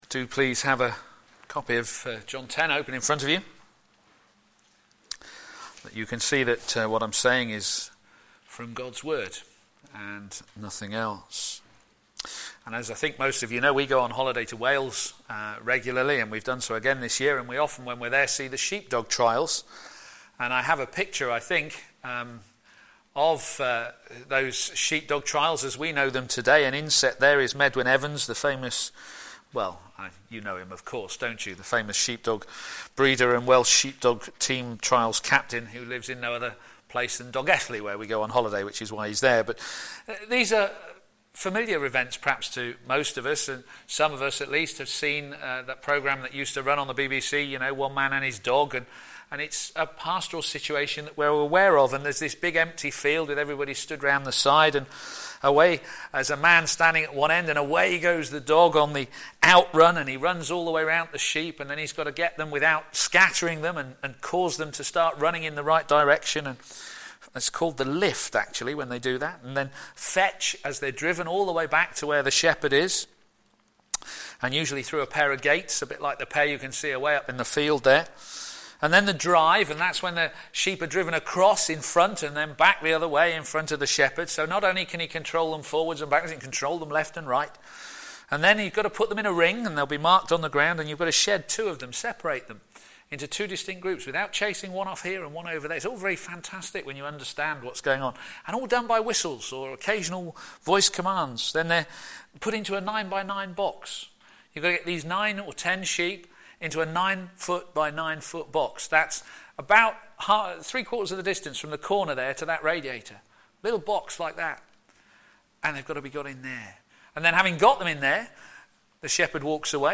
The Shepherd of the Sheep Sermon